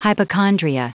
Prononciation du mot hypochondria en anglais (fichier audio)
Prononciation du mot : hypochondria